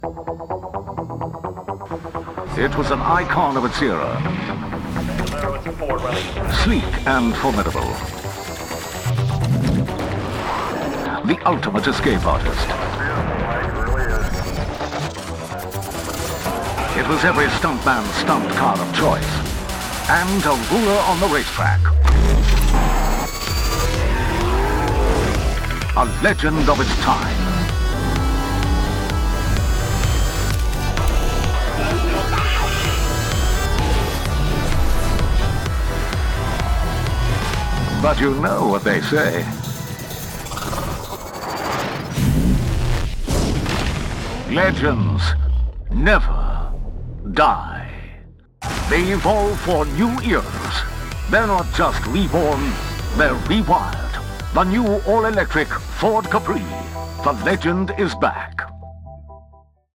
Male
Adult (30-50), Older Sound (50+)
Television Spots